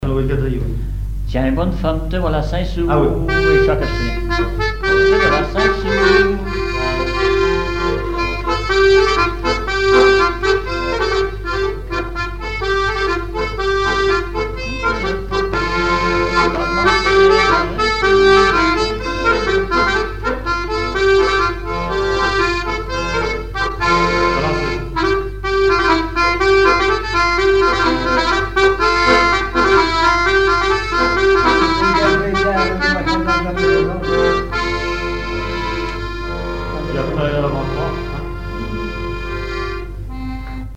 danse : polka
Genre brève
chansons et instrumentaux
Pièce musicale inédite